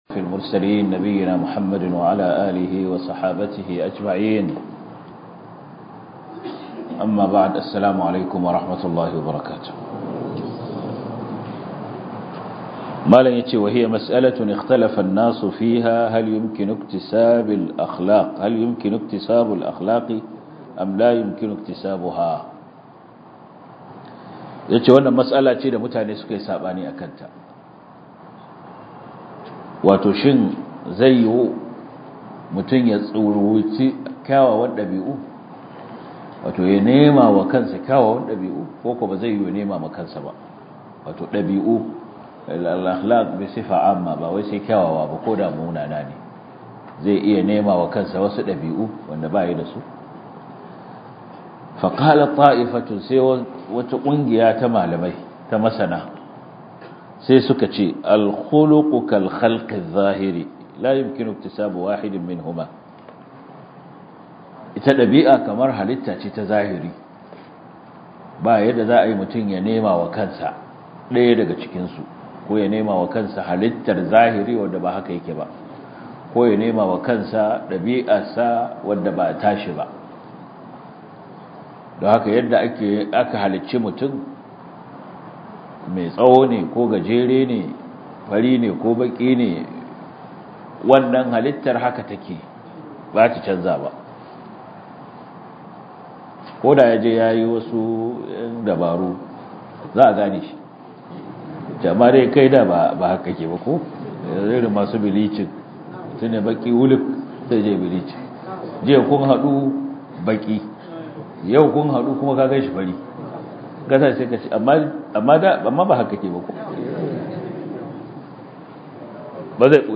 Darussa